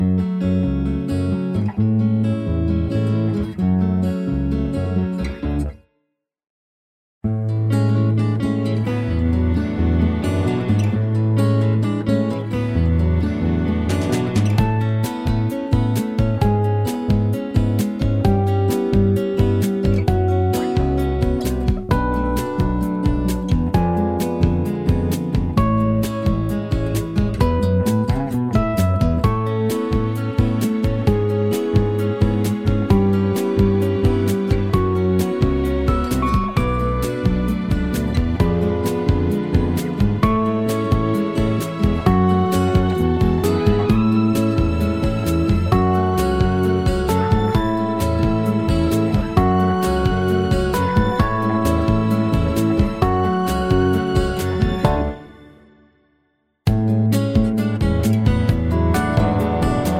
no Backing Vocals Country (Male) 3:12 Buy £1.50